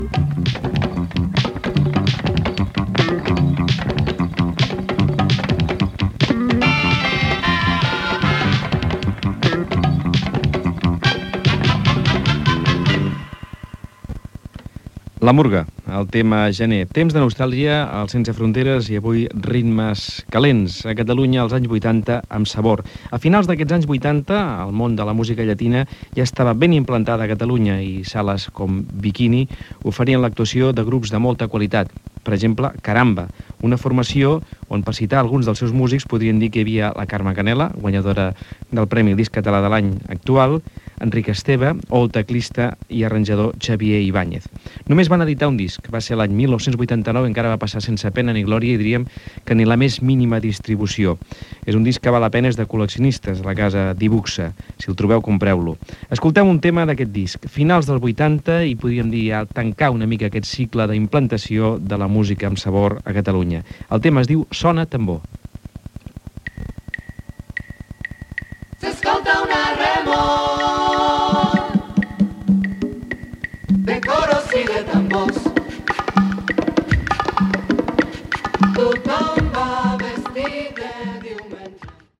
Temps de nostàlgia: final d'un tema de "La murga" i dades sobre "Caramba".
Musical